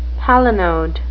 palinode (PAL-uh-noad) noun
Pronunciation:
palinode.wav